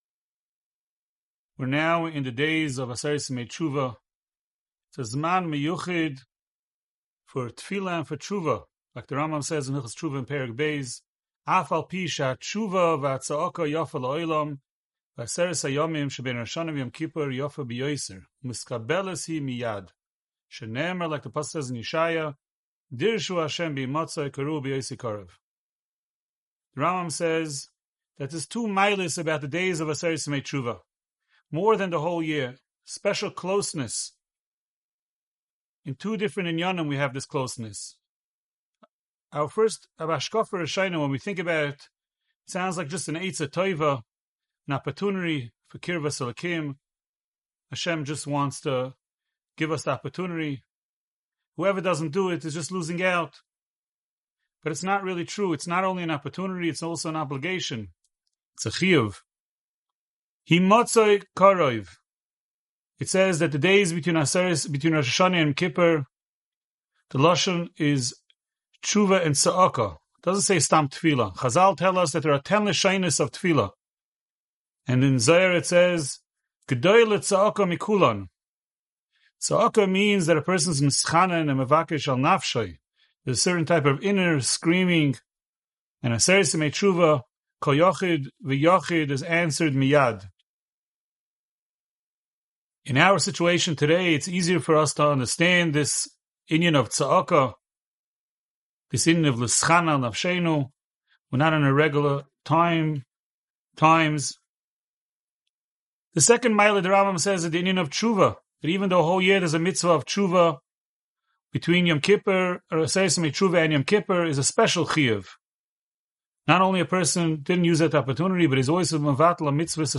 Inspiring Divrei Torah, Shiurim and halacha on Parshas Haazinu from the past and present Rebbeim of Yeshivas Mir Yerushalayim.